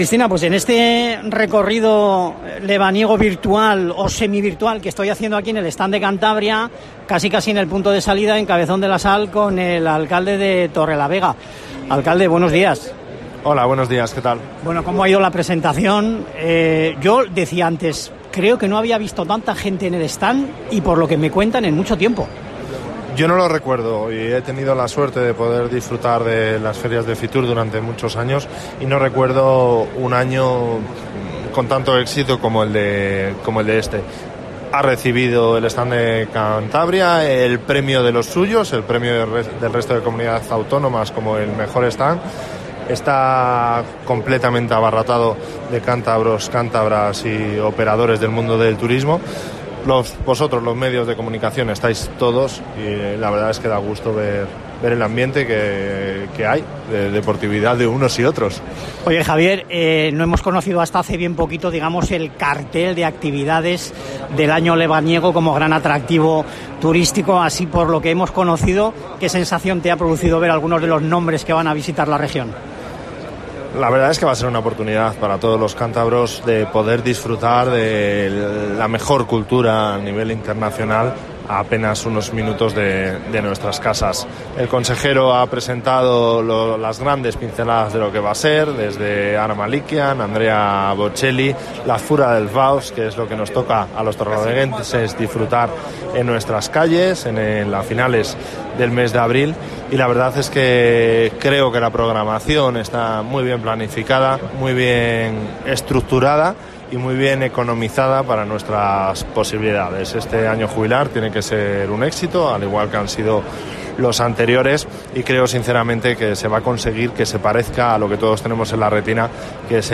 El alcalde, Javier López Estrada, participa en FITUR en la presentación de los Planes de Sostenibilidad Turística en Destino de Cantabria
Entrevista alcalde Torrelavega